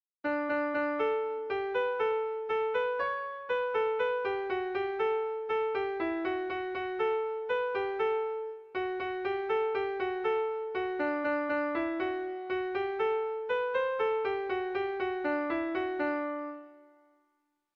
Erlijiozkoa
Zortziko txikia (hg) / Lau puntuko txikia (ip)
7 / 6A / 7 / 6A / 7 / 6A / 7 / 6A (hg) | 13A / 13A / 13A / 13A (ip)